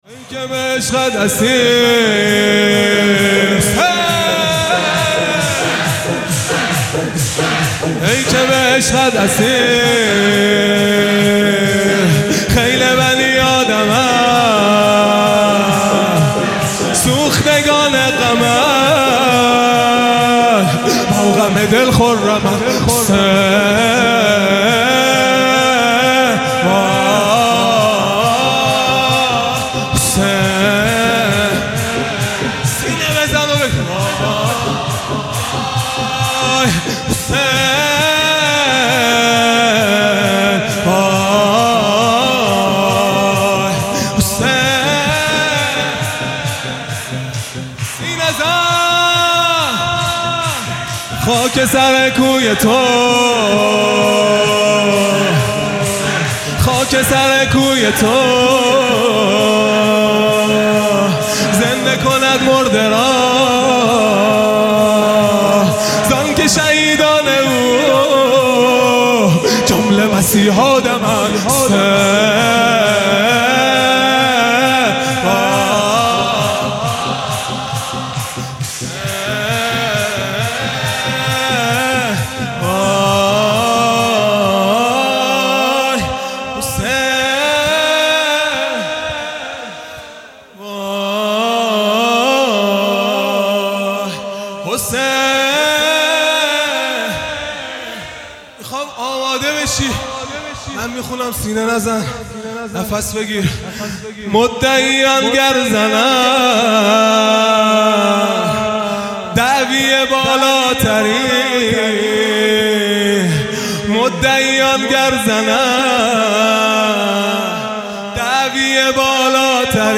خیمه گاه - هیئت بچه های فاطمه (س) - شور | ای که به عشقت اسیر خیل بنی آدمند | ۲۷ تیر ۱۴۰۲
محرم الحرام ۱۴۴5 | شب اول